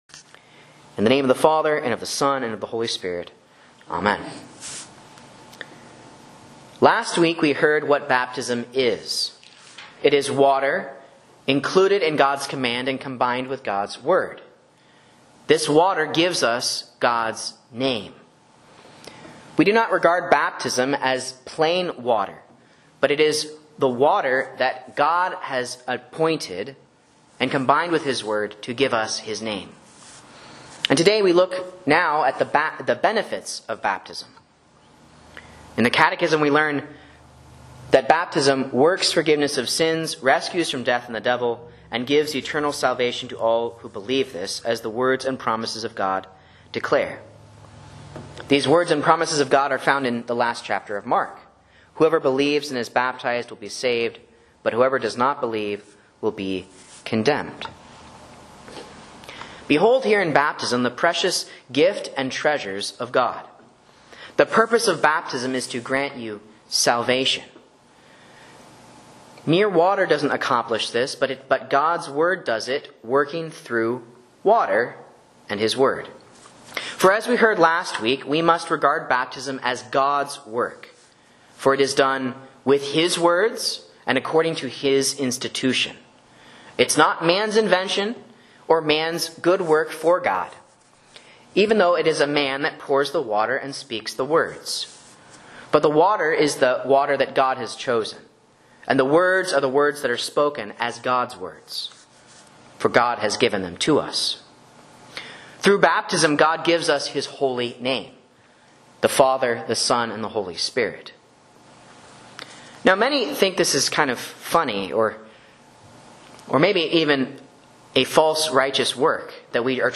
Sermon and Bible Class Audio from Faith Lutheran Church, Rogue River, OR
A Catechism Sermon on Mark 16:16 for Holy Baptism, Question 2